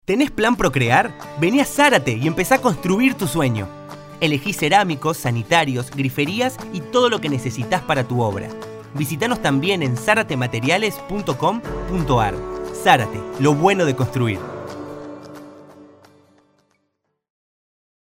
Actor de doblaje · Locutor
Español Argentina
espanol-argentina.mp3